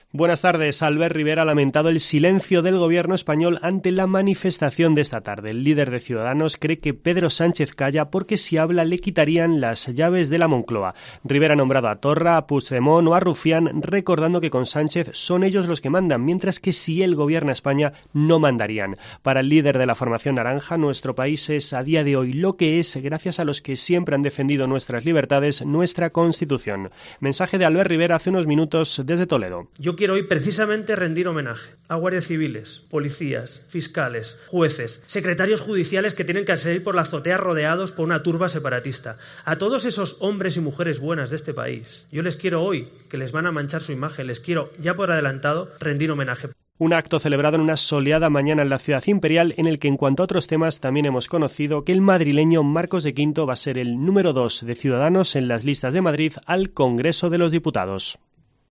Así se ha pronunciado Rivera en un acto público en Toledo